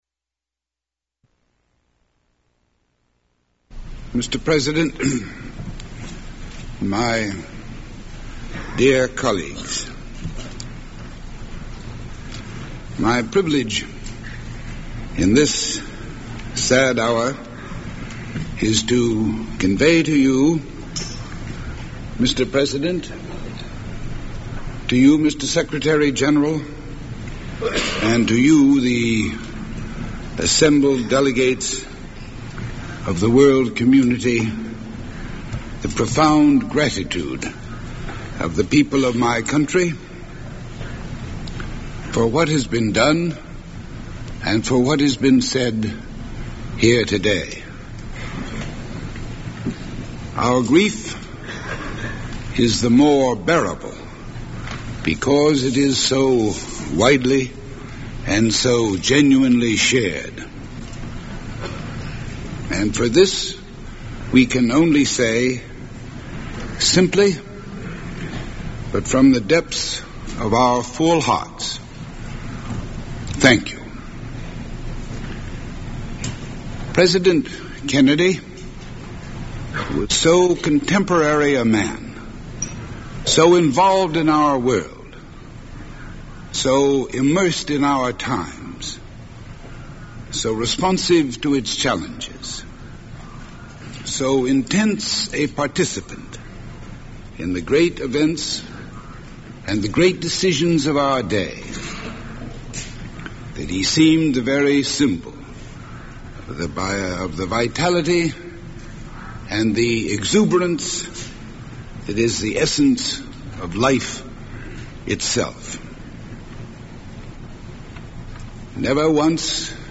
U.S. Ambassador to the United Nations Adlai Stevenson speaks at the U.N. following the death of U.S. President John F. Kennedy